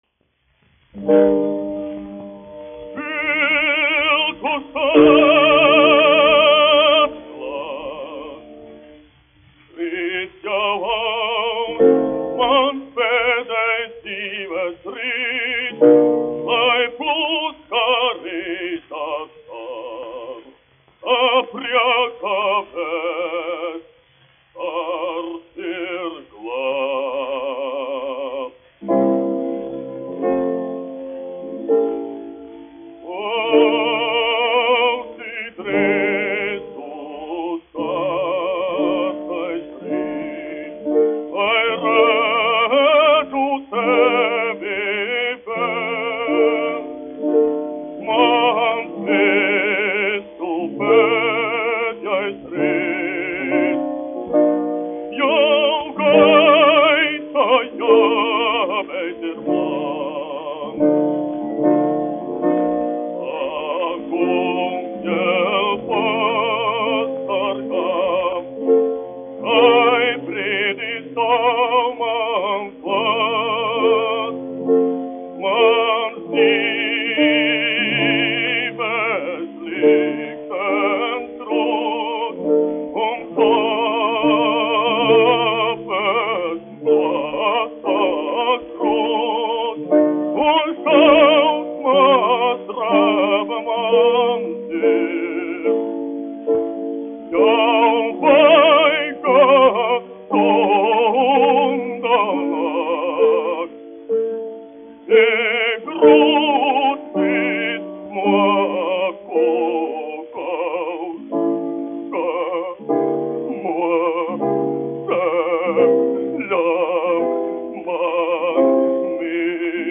Kaktiņš, Ādolfs, 1885-1965, dziedātājs
1 skpl. : analogs, 78 apgr/min, mono ; 25 cm
Operas--Fragmenti, aranžēti
Latvijas vēsturiskie šellaka skaņuplašu ieraksti (Kolekcija)